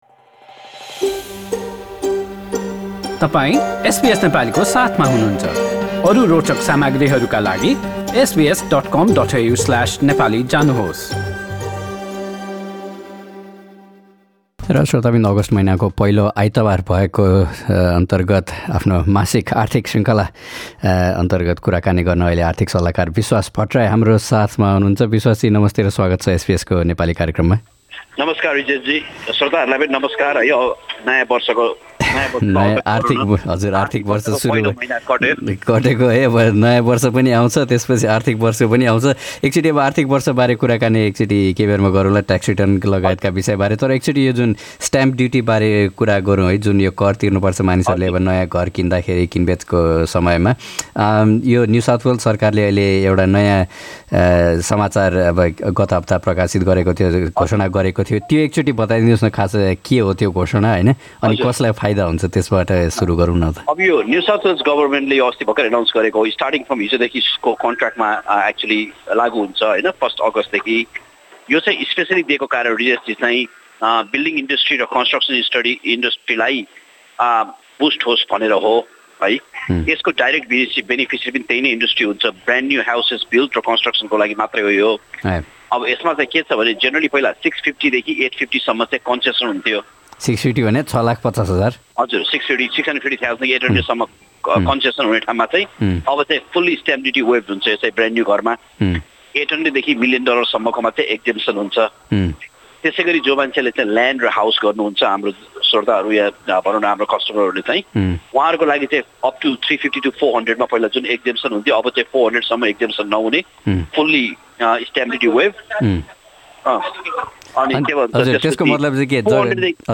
यी विषय सहितको कुराकानी माथि रहेको मिडिया प्लेयरबाट सुन्नुहोस्।